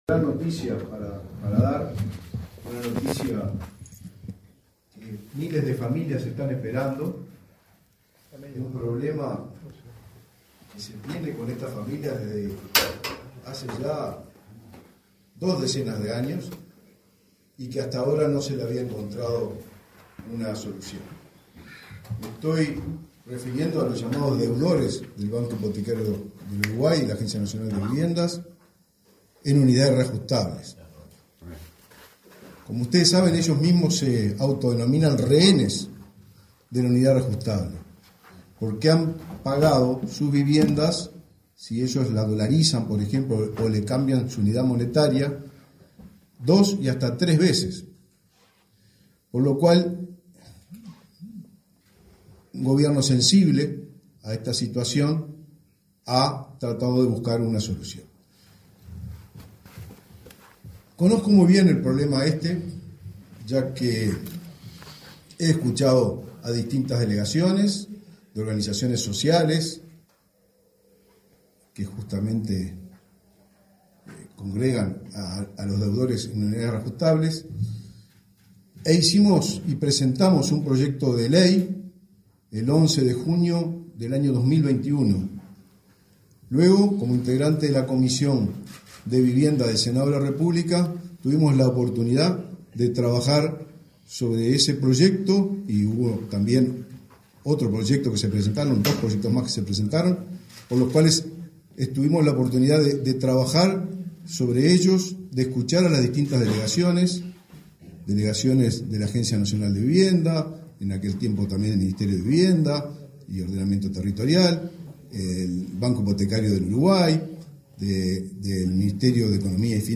Palabras del ministro del MVOT, Raúl Lozano
Palabras del ministro del MVOT, Raúl Lozano 07/10/2024 Compartir Facebook X Copiar enlace WhatsApp LinkedIn El Ministerio de Vivienda y Ordenamiento Territorial (MVOT) realizó, este 7 de octubre, una conferencia de prensa para informar de los avances del Plan UR (ley 20.237). El ministro Raúl Lozano disertó en el evento.